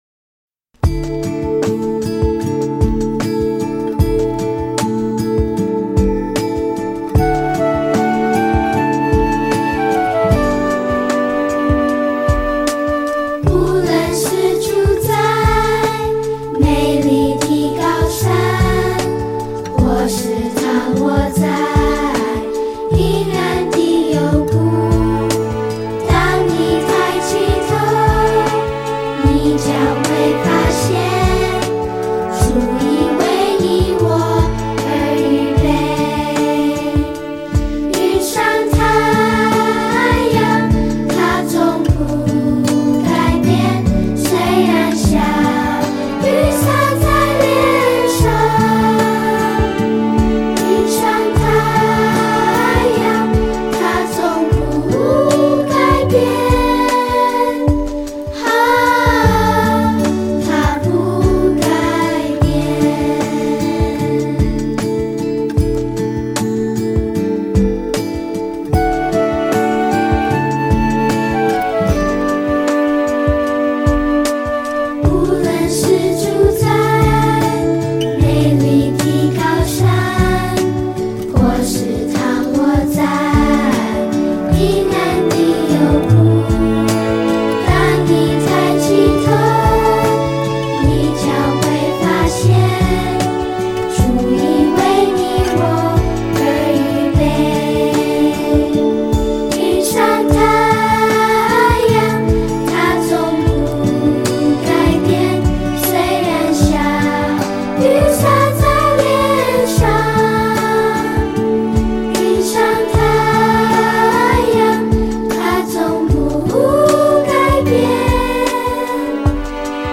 跟唱建议 动作跟唱，有视频和音频两部分。视频里有动作演示，音频里歌会自动重复三遍。